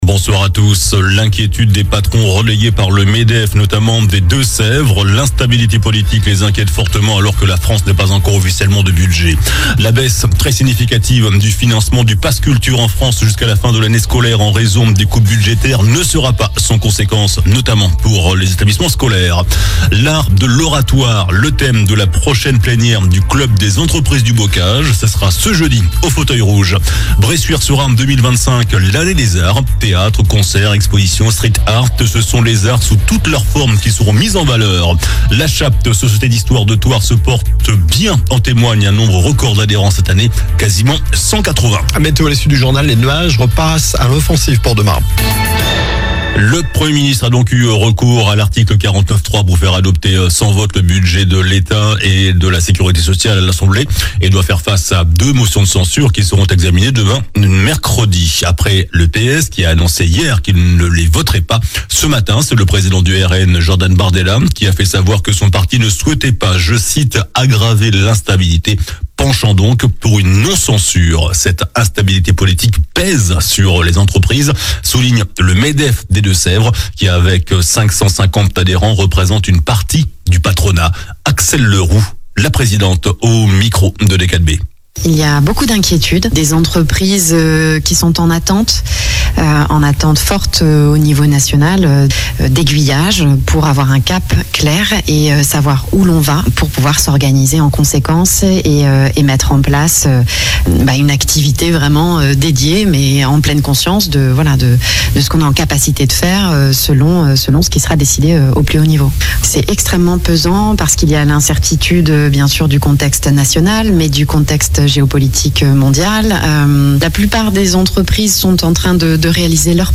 JOURNAL DU MARDI 04 FEVRIER ( SOIR )